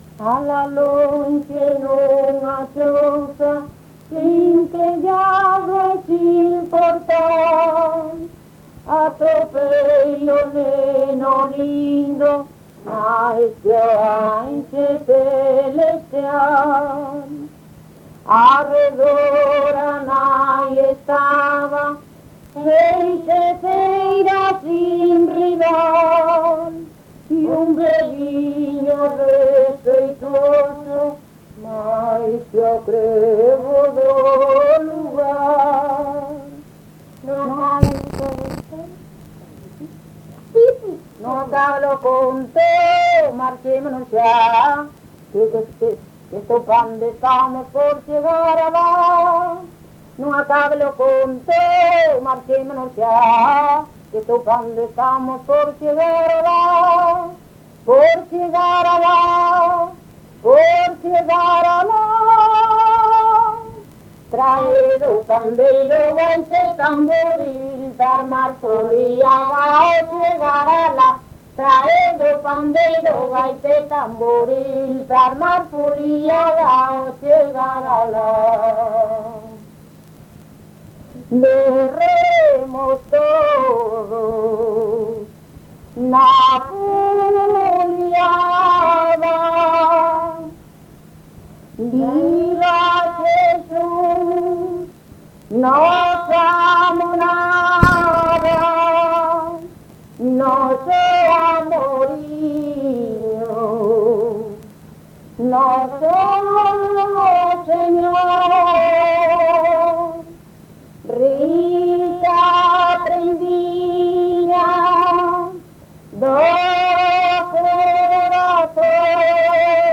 Áreas de coñecemento: LITERATURA E DITOS POPULARES > Cantos narrativos
Lugar de compilación: Chantada - A Grade (San Vicente) - Quintá
Soporte orixinal: Casete
Instrumentación: Voz
Instrumentos: Voz feminina